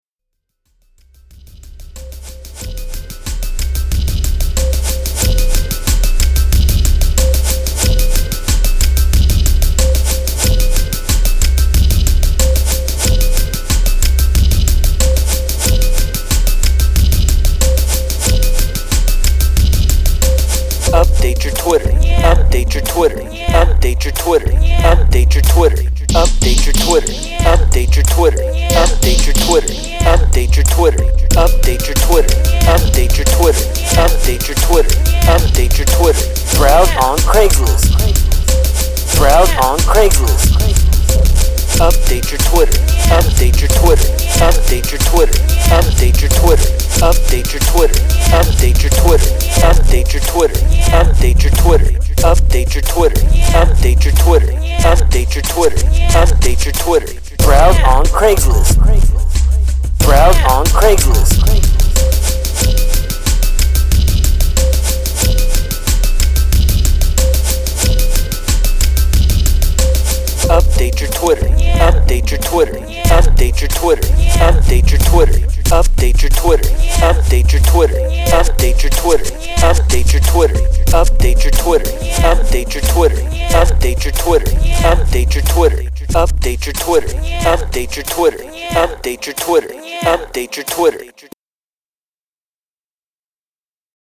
This beat is craptastic.